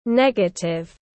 Negative /ˈneɡ.ə.tɪv/